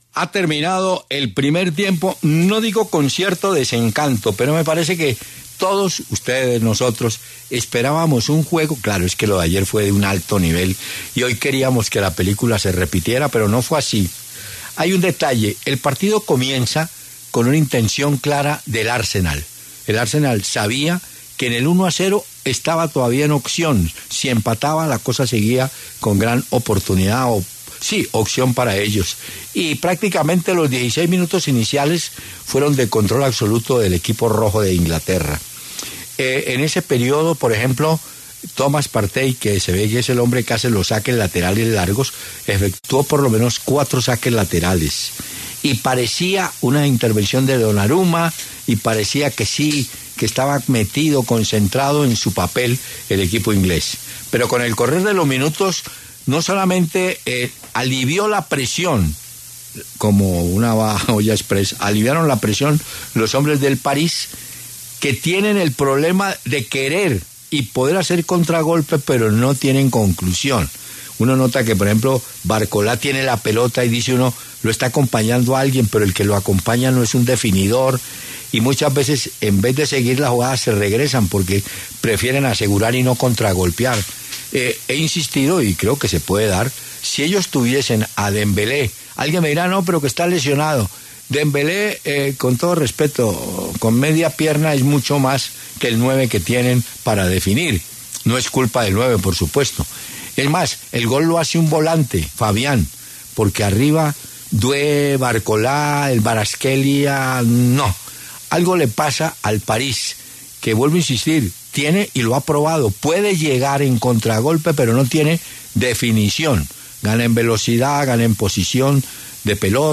Ahora, el reconocido periodista Hernán Peláez analizó el primer tiempo del juego y destacó que el PSG que se vio en la primera mitad es uno “muy diferente al de Messi, Mbappé y Neymar”, teniendo en cuenta que el plantel de ese entonces no logró llegar a la final de la Liga de Campeones, pues su falta de juego claro lo hizo caer eliminado frente al Real Madrid.